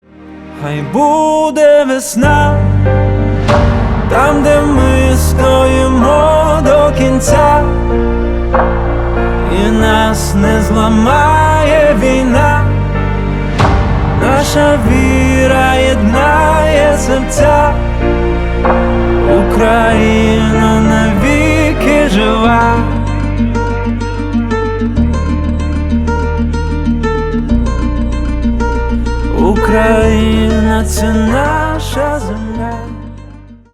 • Качество: 320 kbps, Stereo
Поп Музыка
грустные
спокойные